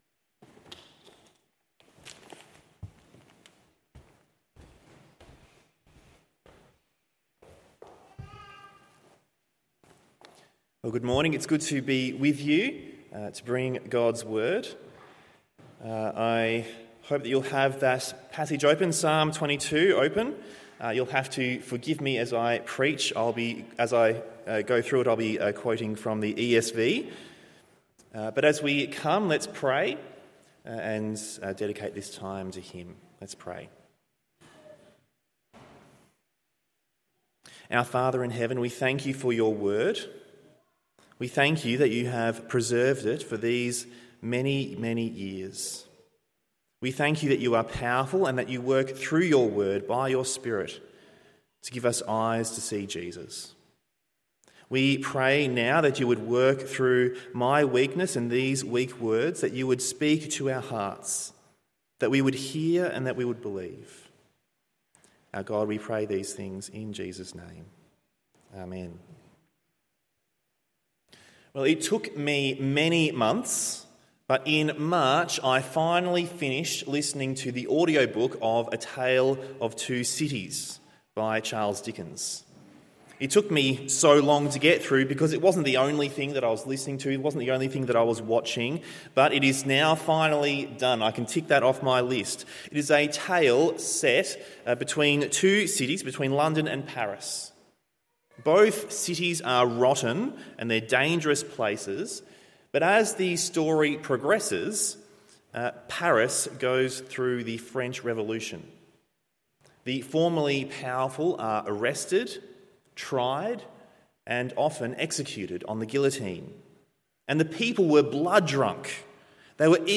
Morning Service Psalm 22…